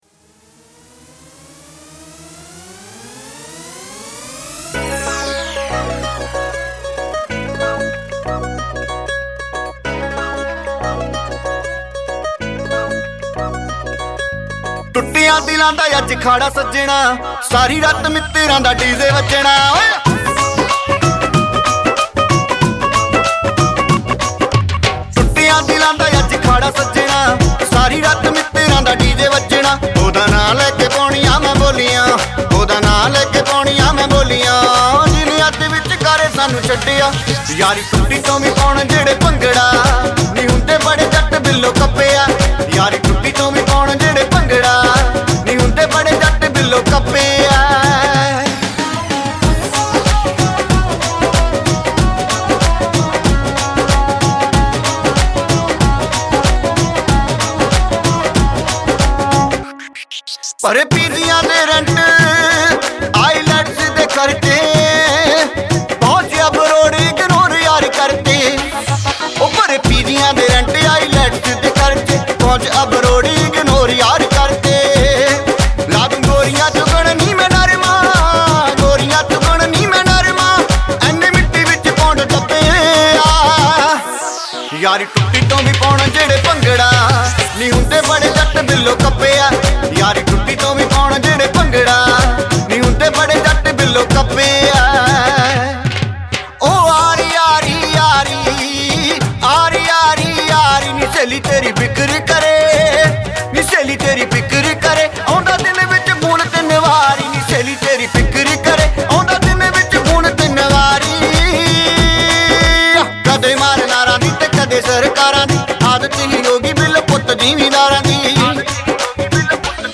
Punjabi Bhangra MP3 Songs